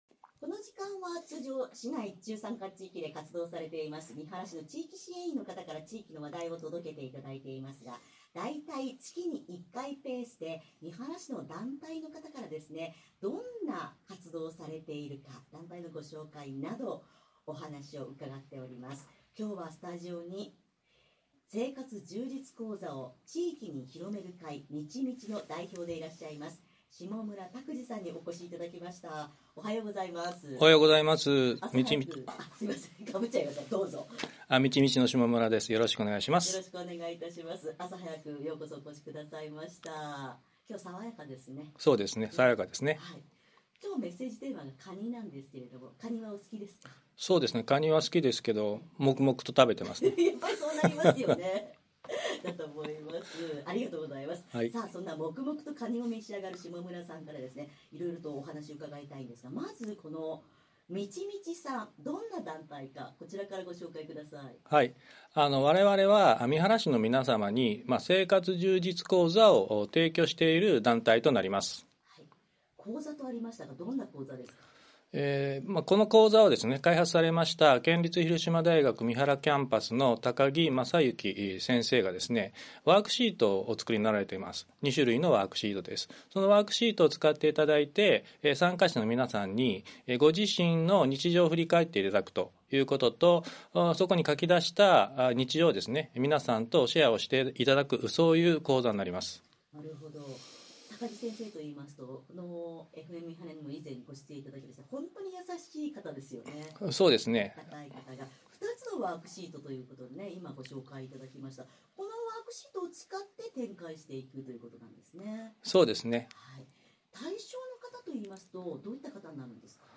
番組開始前のスタジオの前で